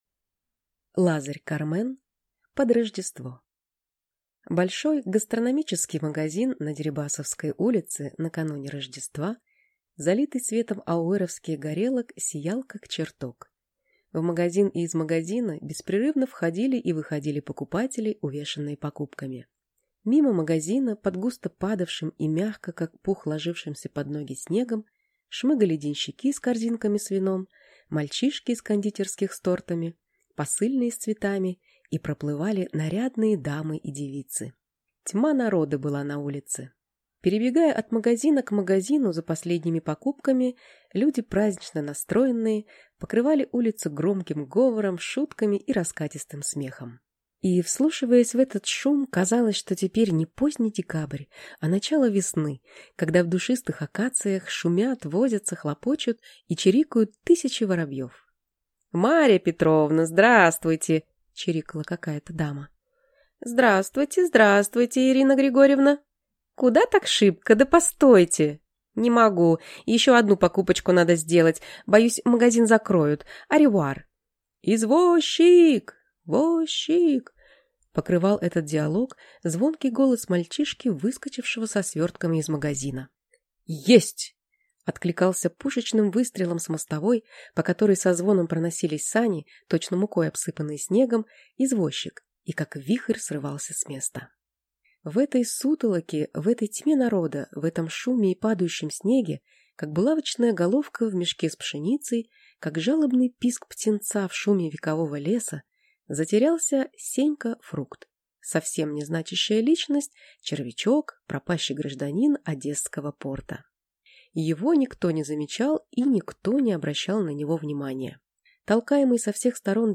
Аудиокнига <Под рождество> | Библиотека аудиокниг